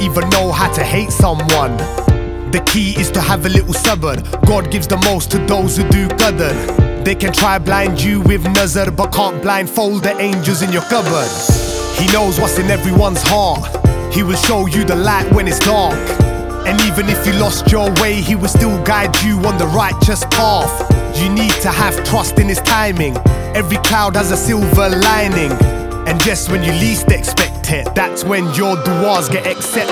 • World